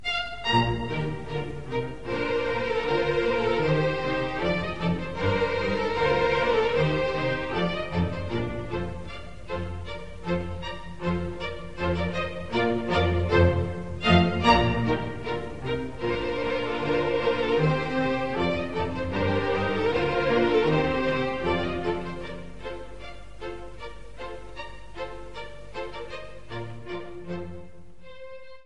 Symphony for violin orchestra B Flat Major (I. Moderato